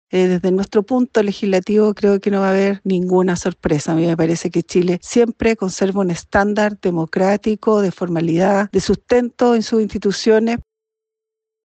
Mientras tanto, la senadora del PPD, Loreto Carvajal, aclaró que desde su sector no habrá sorpresas durante la toma de posesión.